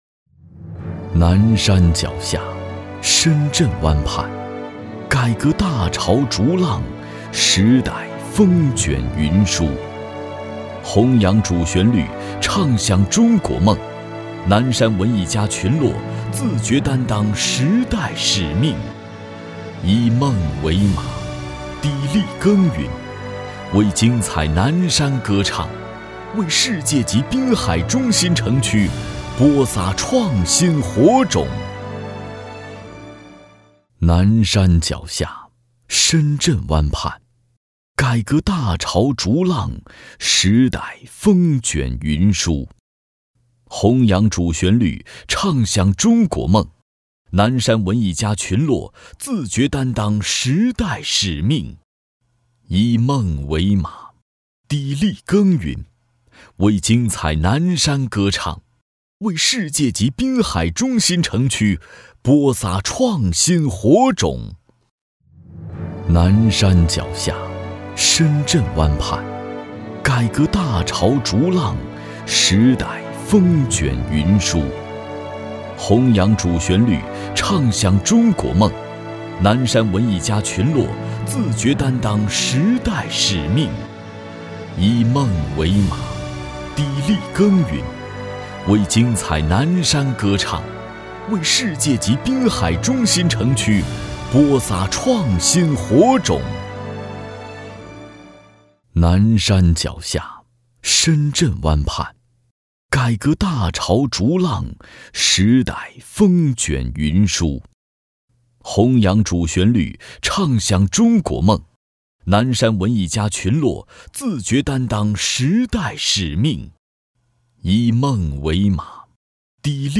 国语中年低沉 、大气浑厚磁性 、沉稳 、娓娓道来 、男纪录片 、100元/分钟男S346 国语 男声 纪录片 红纪录片音—红色记忆 低沉|大气浑厚磁性|沉稳|娓娓道来